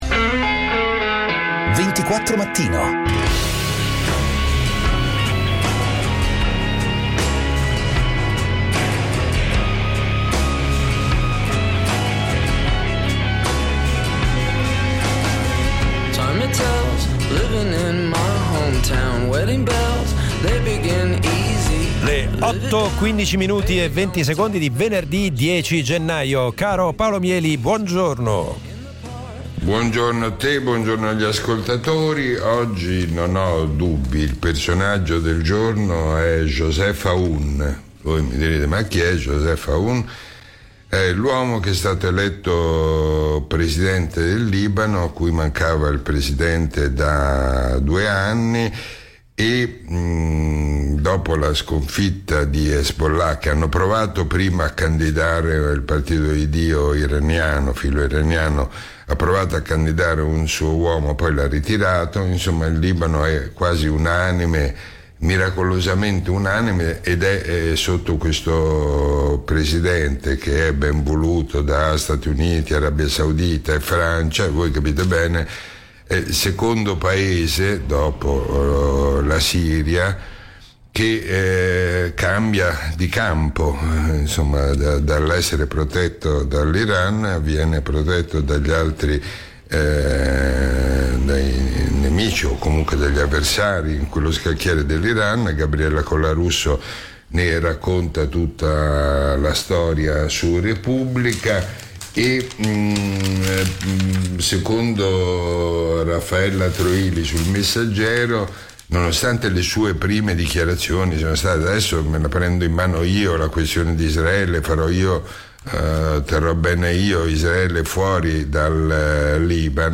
Prima l'imperdibile appuntamento quotidiano con Paolo Mieli per commentare i fatti della giornata. Poi gli ospiti dal mondo della politica, dell'economia, della cronaca, della giustizia: i protagonisti dei fatti o semplicemente chi ha qualcosa di interessante da dire o su cui riflettere, compresi gli ascoltatori.